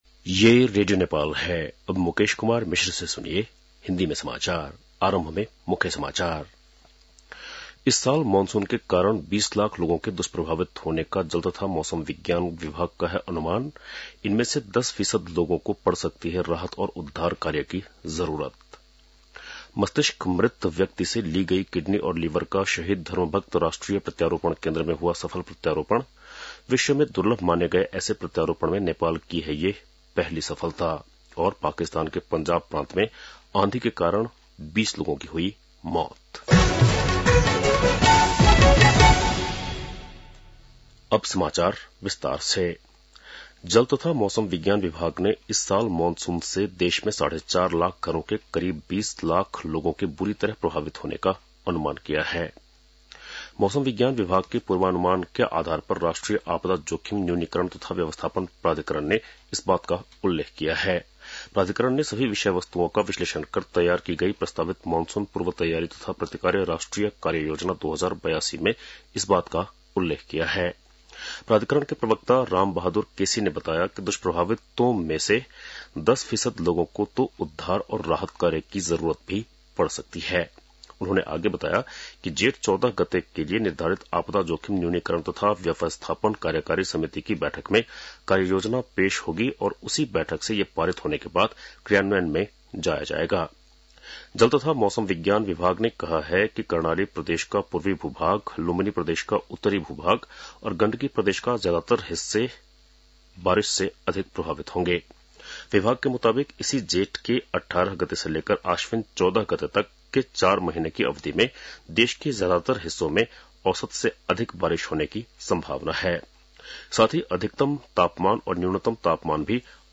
बेलुकी १० बजेको हिन्दी समाचार : ११ जेठ , २०८२
10-pm-hindi-news-.mp3